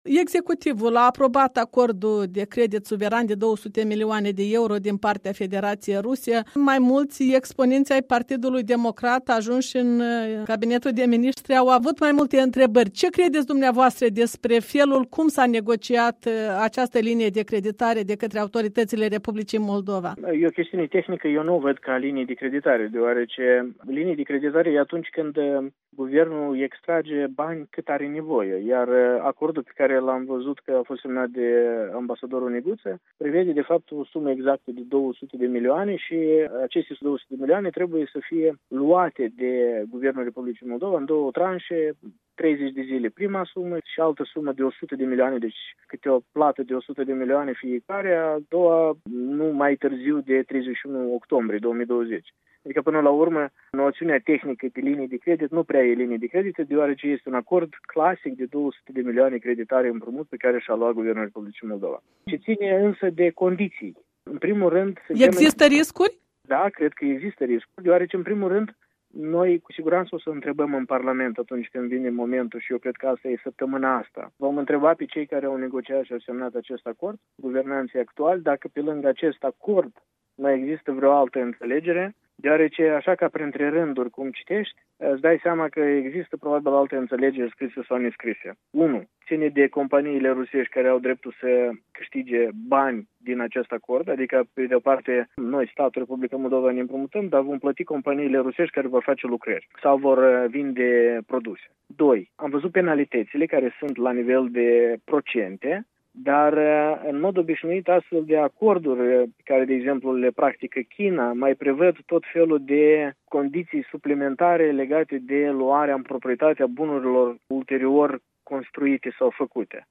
Interviu cu Andrian Candu